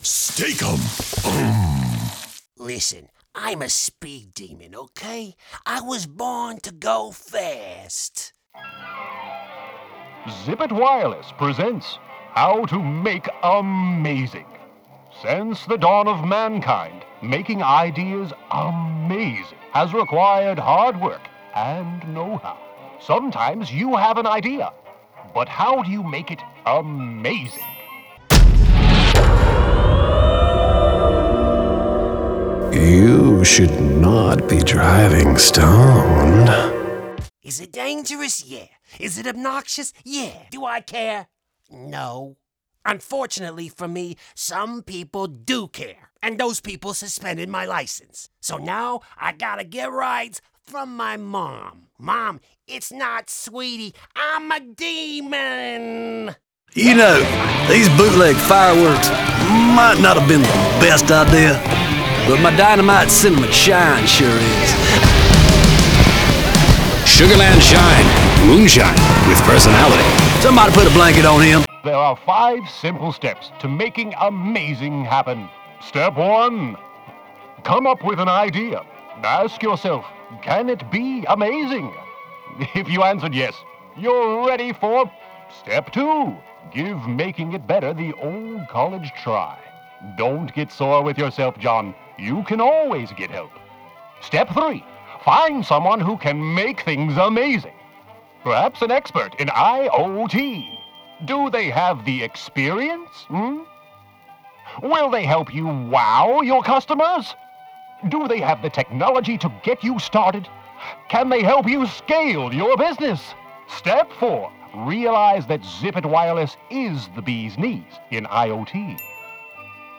16 Years + Experienced Versatile Male VO Artist for Commercial, Characters, Jingles and More
Spanish, French, Irish
Young Adult
Middle Aged
Cartoon / Animation